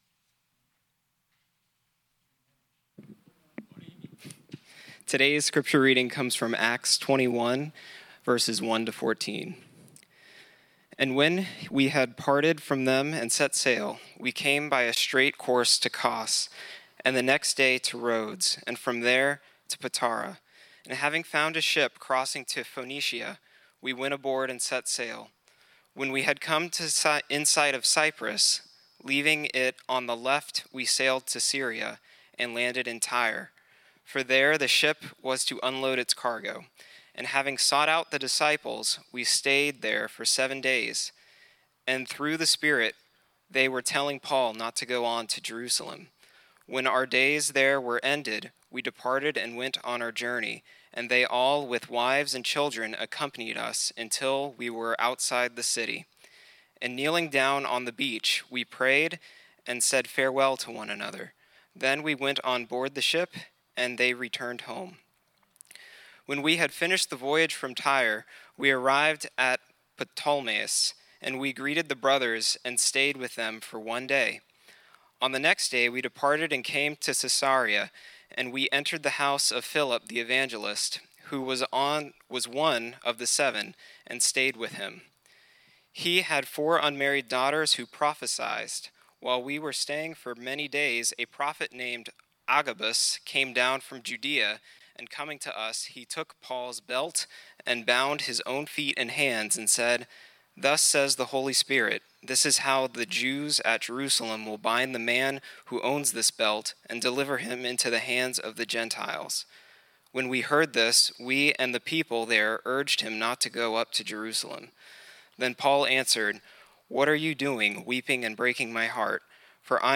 Read the message here: The Will of the Lord Be Done Sermon Manusript